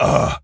pgs/Assets/Audio/Animal_Impersonations/gorilla_hurt_06.wav at master
gorilla_hurt_06.wav